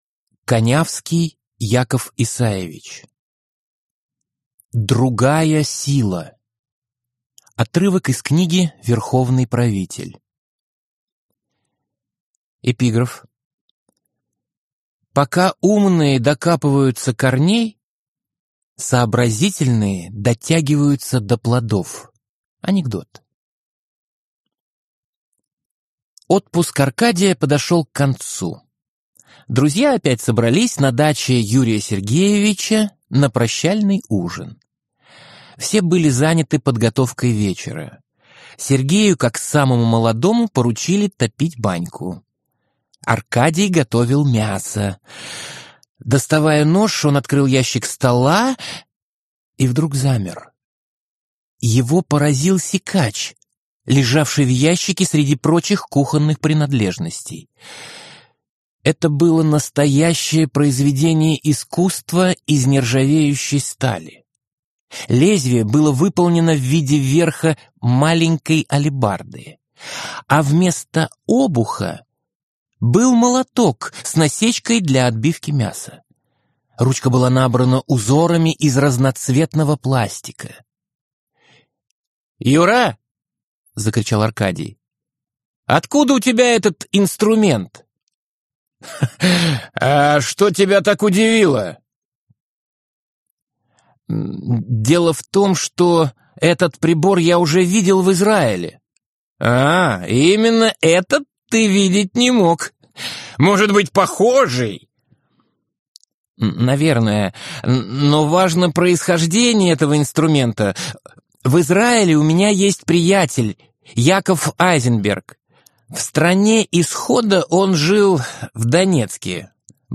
Аудиокнига Другая сила | Библиотека аудиокниг